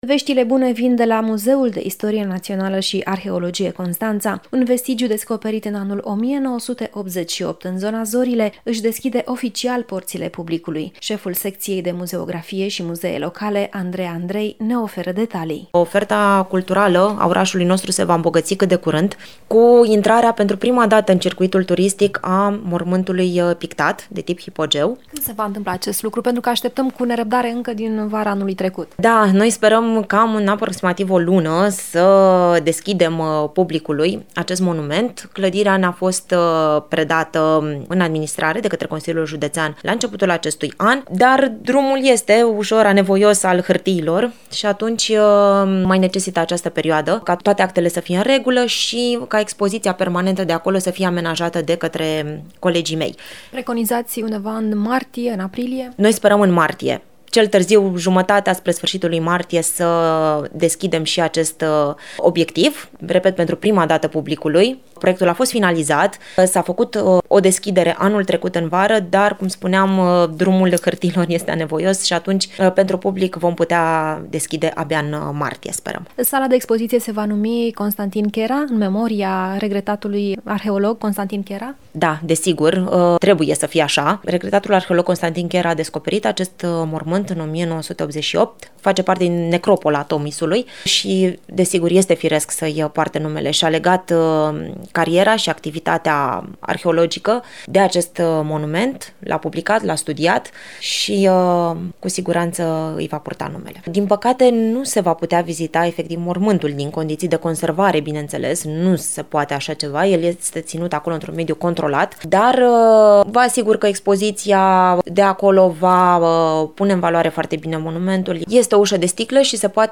interviu